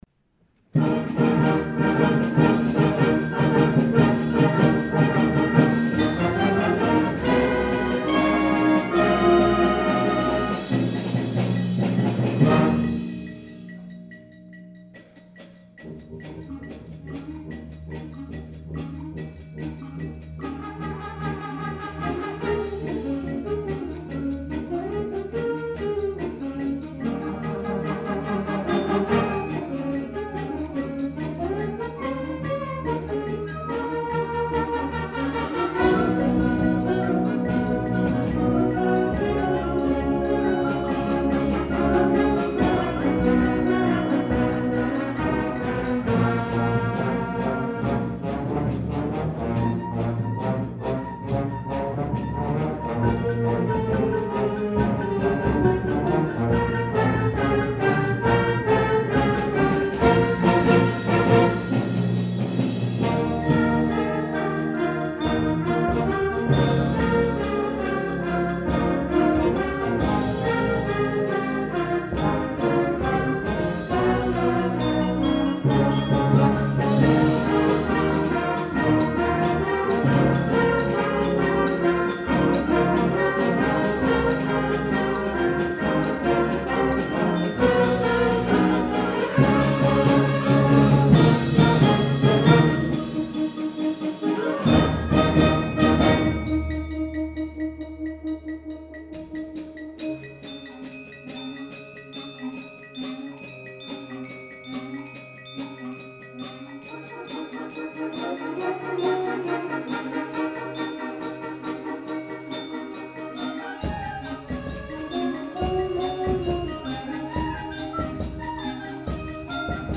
2013 Winter Concert